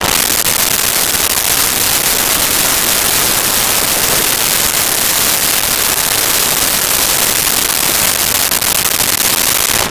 Lake And Water
Lake and Water.wav